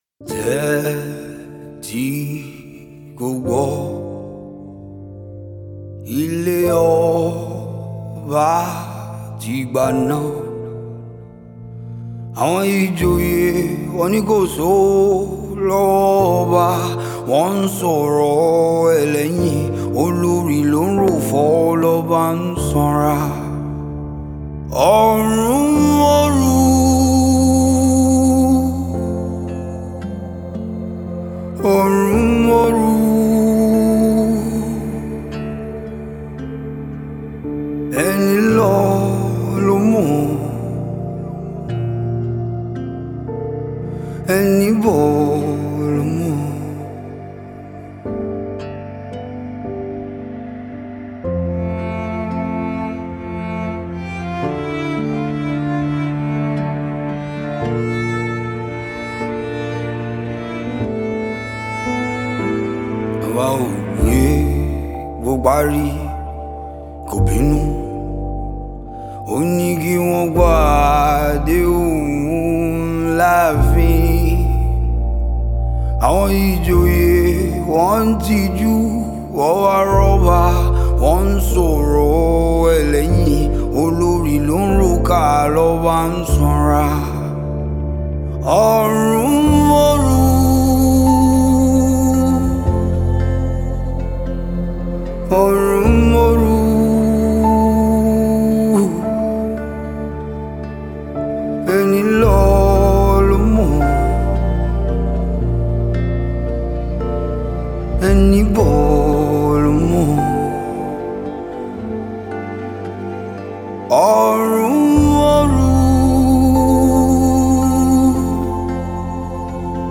Nigerian Music